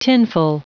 Prononciation du mot tinful en anglais (fichier audio)
Prononciation du mot : tinful